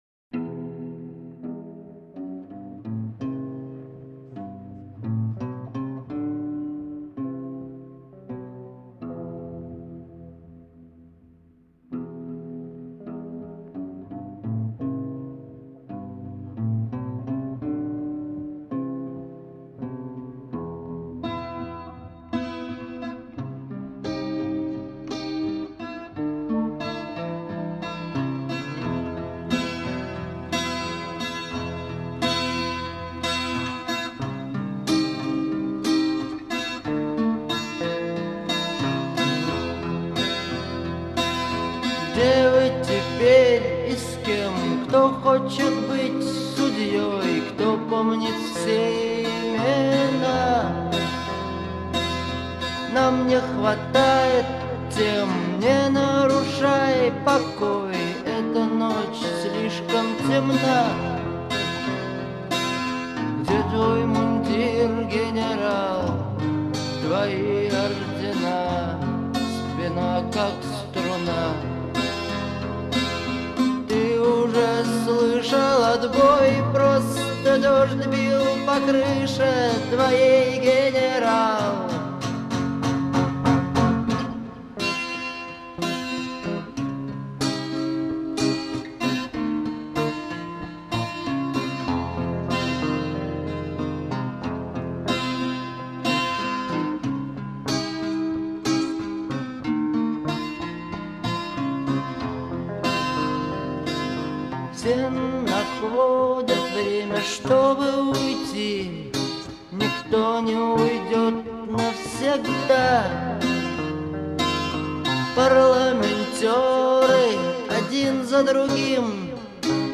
это яркий пример русского рока
мощные гитарные рифы
создавая атмосферу напряженности и искренности.